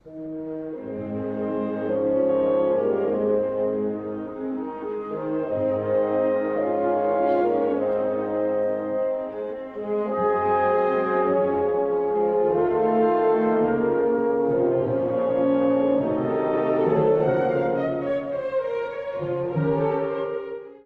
古い音源なので聴きづらいかもしれません！（以下同様）
第3楽章｜さまざまな性格のインテルメッツォ
遊び心ある伴奏と、愛らしい旋律が交錯し、曲全体のバランスを整えています。
どこか気まぐれで、夢の中をふわりと漂うような時間。